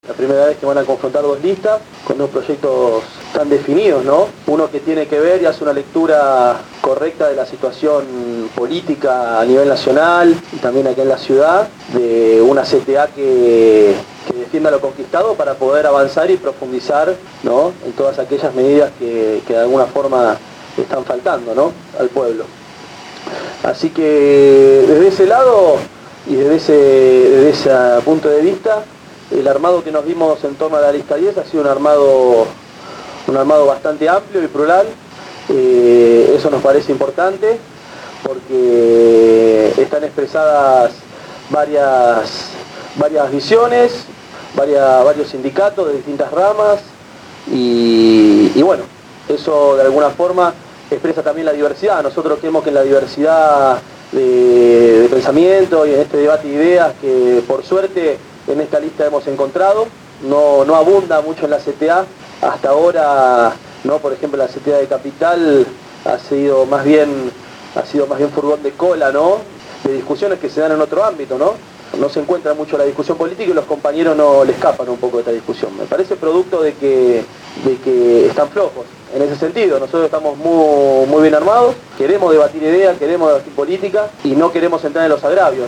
El último lunes se realizó en las instalaciones de Radio Gráfica la charla-debate: «Los Trabajadores y el Proyecto Nacional«, todo ésto de cara a las elecciones internas de la Central de Trabajadores de la Argentina el próximo 23 de Septiembre.